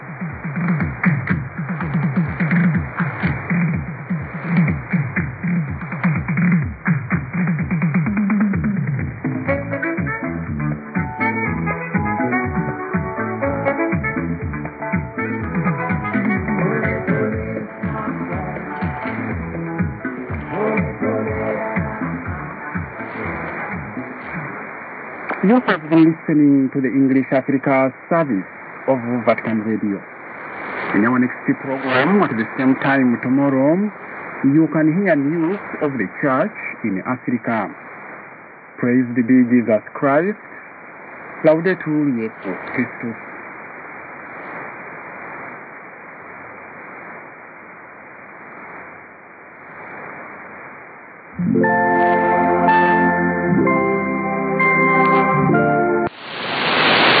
・このＨＰに載ってい音声(ＩＳとＩＤ等)は、当家(POST No. 488-xxxx)愛知県尾張旭市で受信した物です。